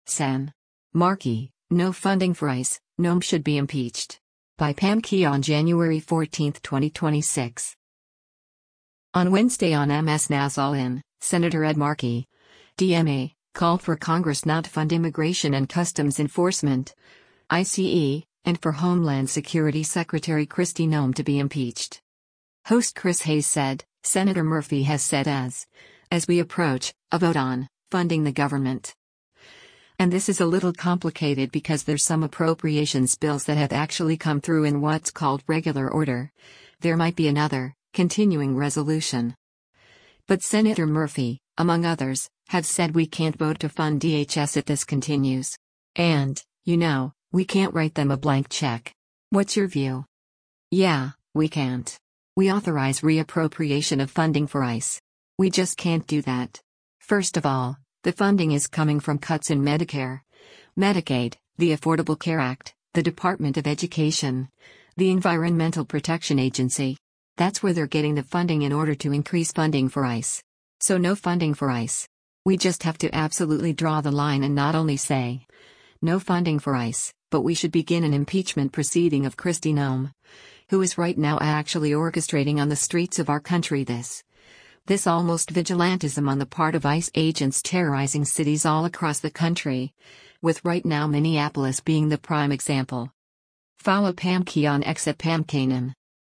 On Wednesday on MS NOW’s “All In,” Sen. Ed Markey (D-MA) called for Congress not fund Immigration and Customs Enforcement (ICE) and for Homeland Security Secretary Kristi Noem to be impeached.